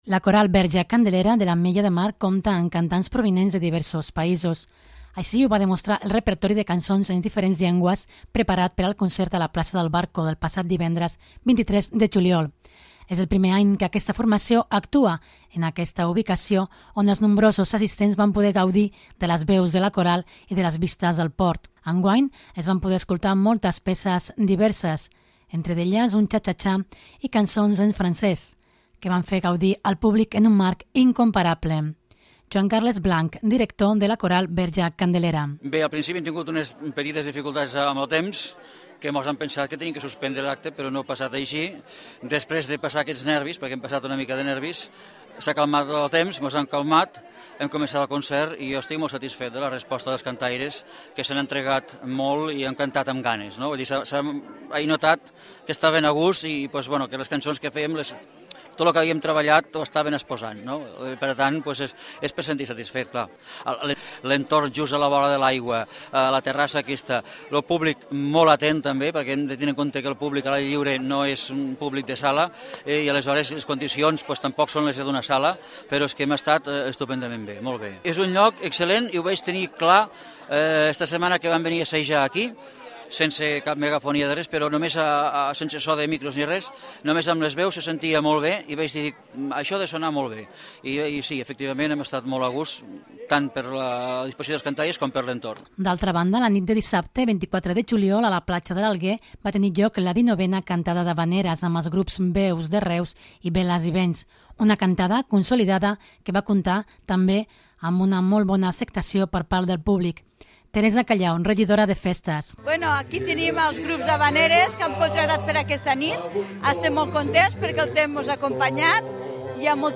La Coral Verge Candelera de l'Ametlla de Mar compta amb cantants provinents de diversos països.
L'actuació de la Coral Verge Candelera i la 19ena Trobada d'Havaneres són una cita ineludible per a caleros i visitants.